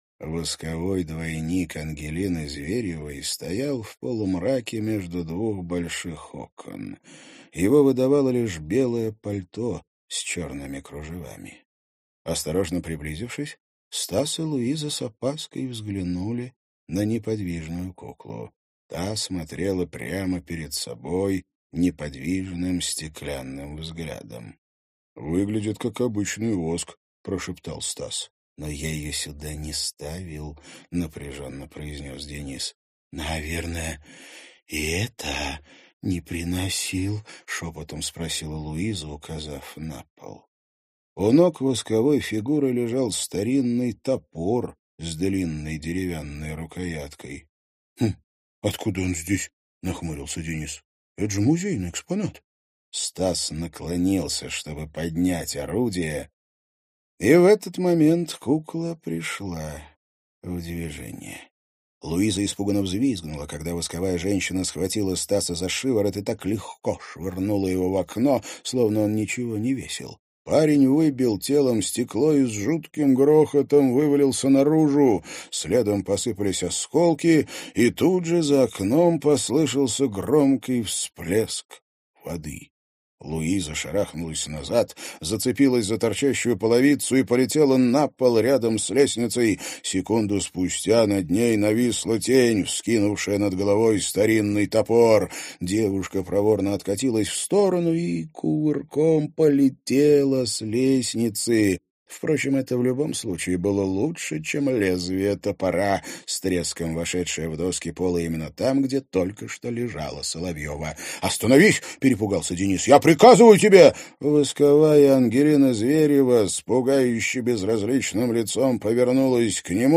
Аудиокнига Пандемониум. Дом у Змеиного озера | Библиотека аудиокниг